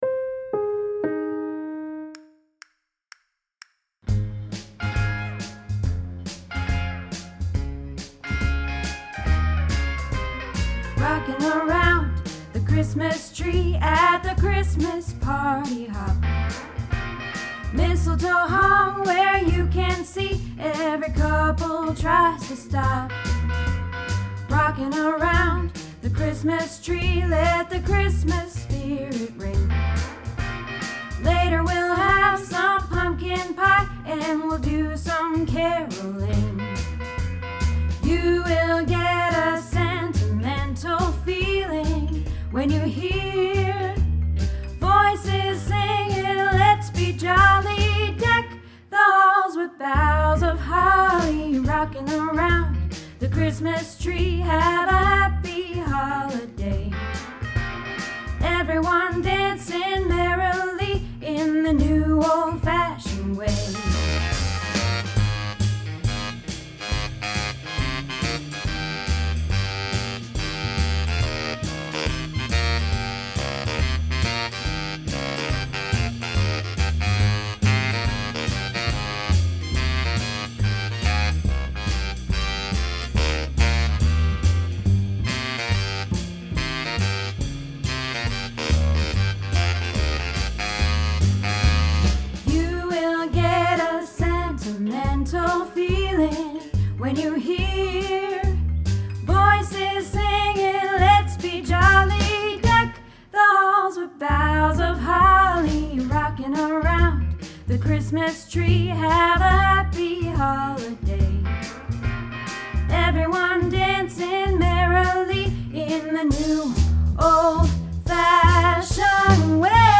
Rockin Around the Christmas Tree - Alto